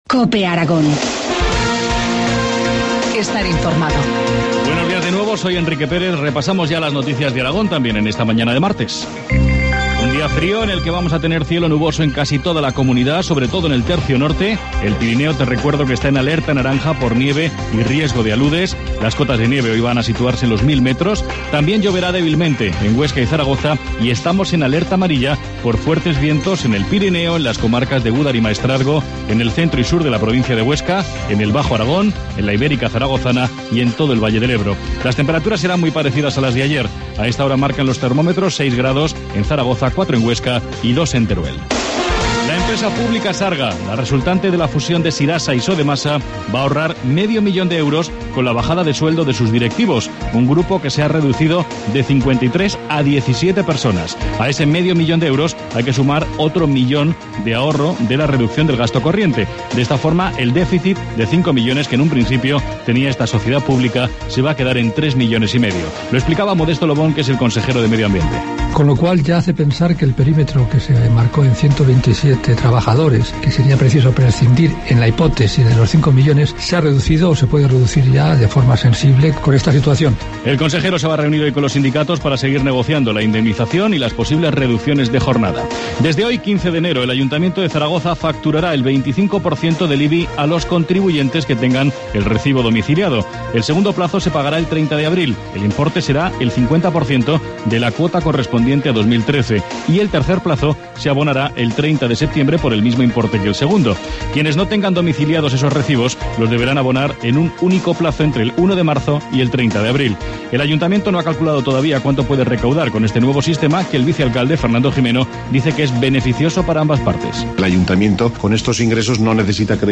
Informativo matinal, martes 15 de enero, 8.25 horas